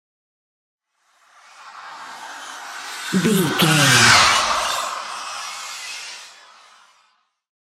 Airy pass by horror squeal
Sound Effects
In-crescendo
Atonal
ominous
eerie